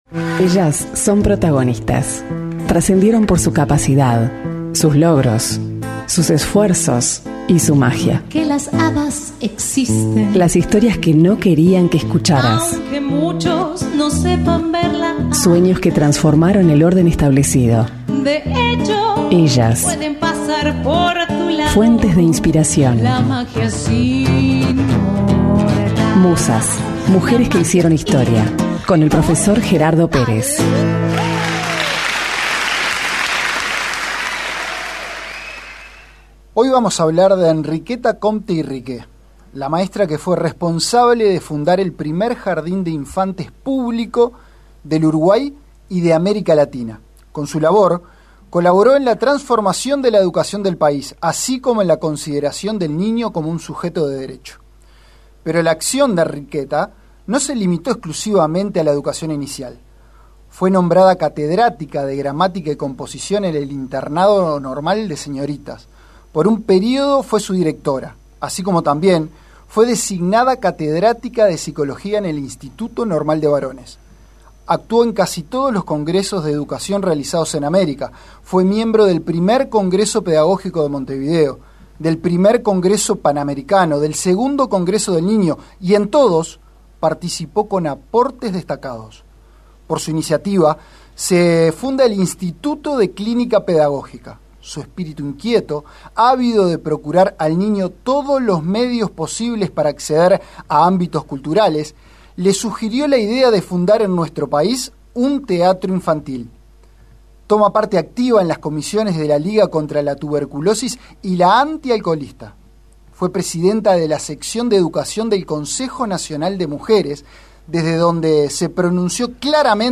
Programa radial Abran Cancha FM DEL SOL